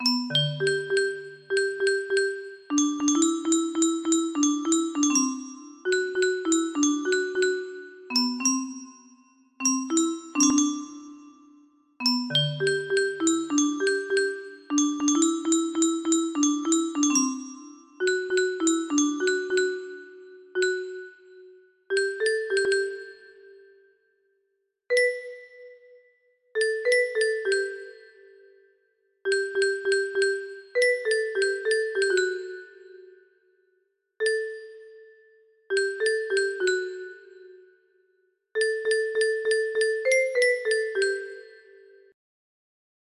Anak Medan music box melody